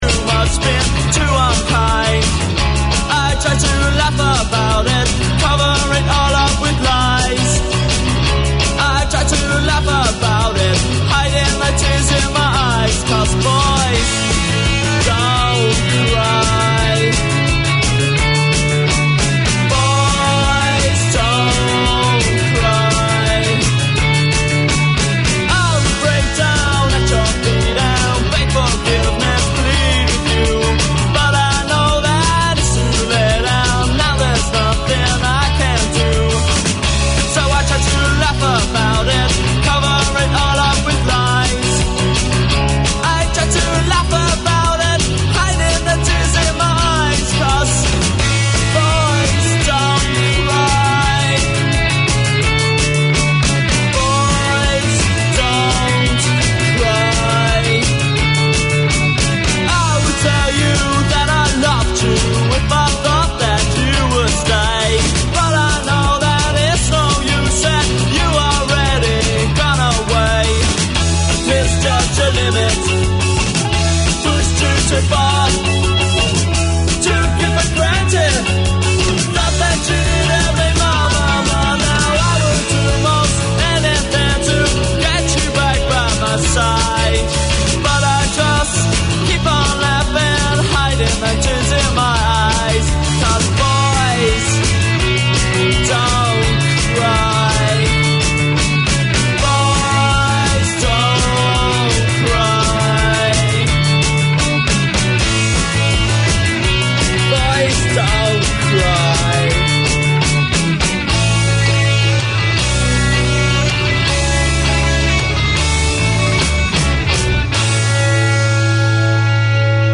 Unfiltered Conversations: Chai & Chat, is a safe and open space where young South Asian girls, can openly discuss, debate, and ask questions about relationships, culture, identity, and everything in between. They are on-air to break the stigma, share experiences, and support each other through honest conversations over a cup of chai.